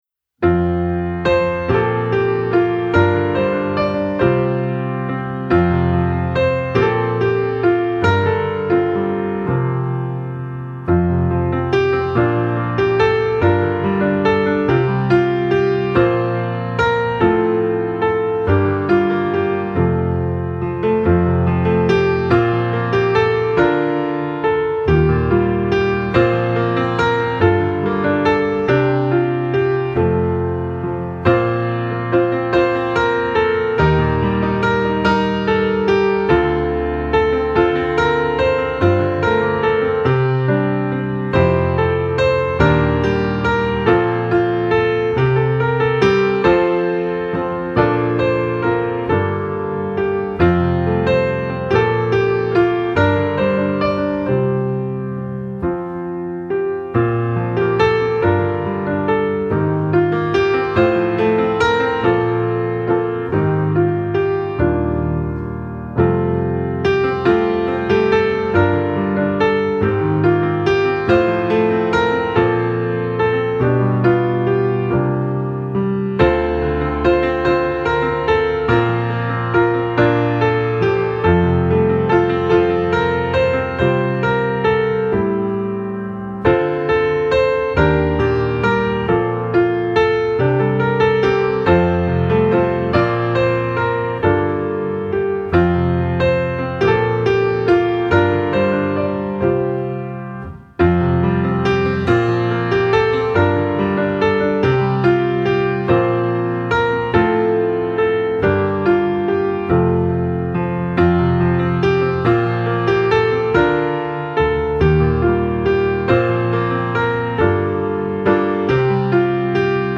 Hymn
7b3ea-lovedivinealllovesexcelling-pianoonly.mp3